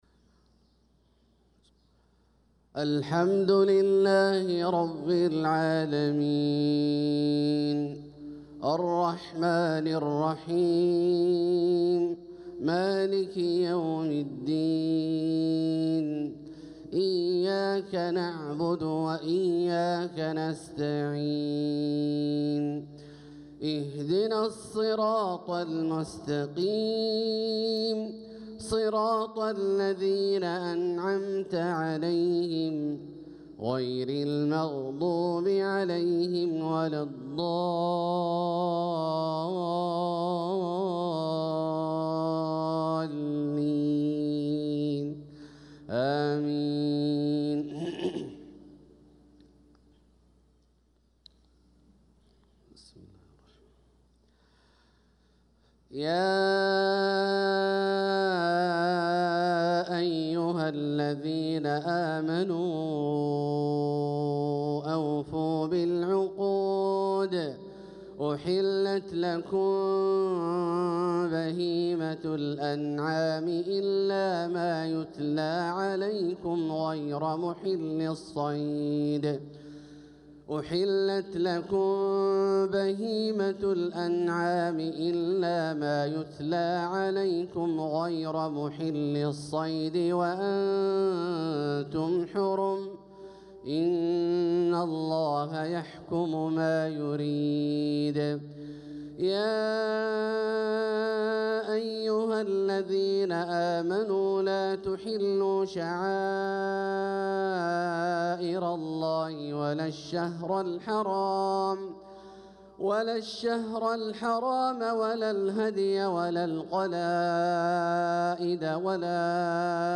صلاة الفجر للقارئ عبدالله الجهني 22 صفر 1446 هـ